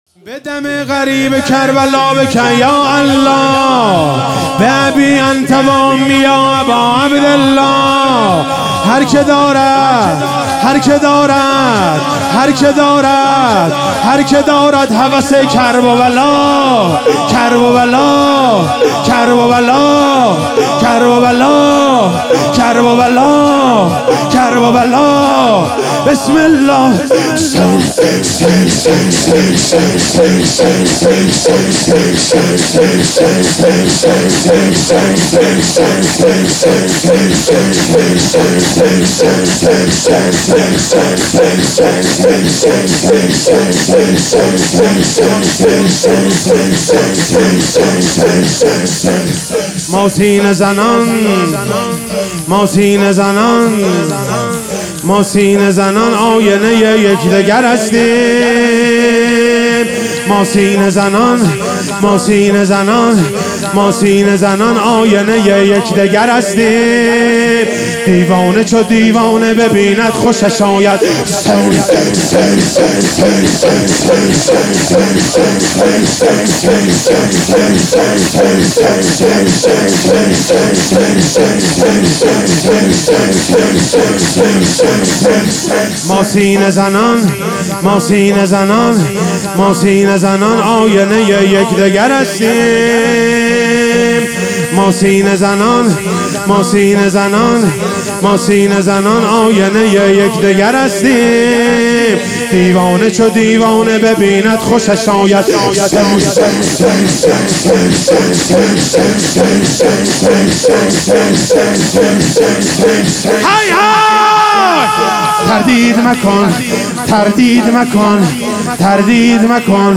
محرم1400 - شب پنجم - شور - بدم الغریب کربلا